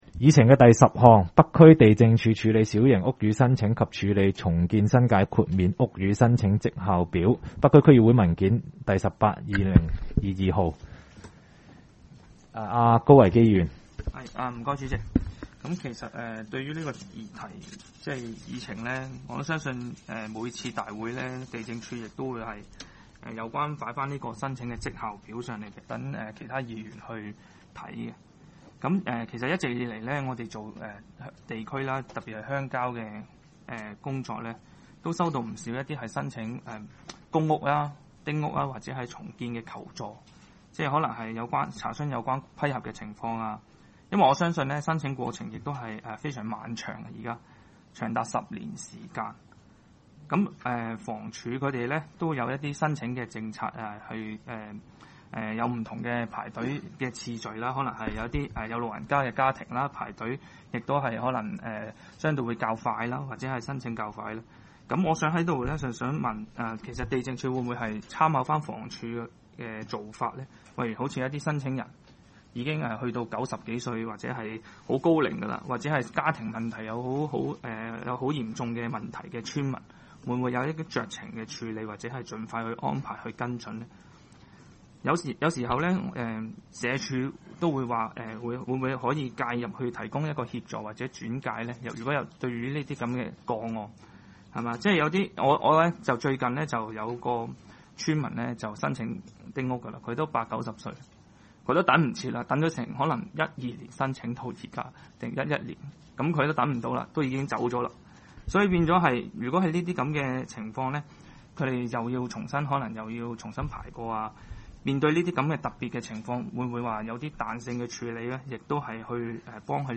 区议会大会的录音记录
北区区议会第十一次会议
北区民政事务处会议室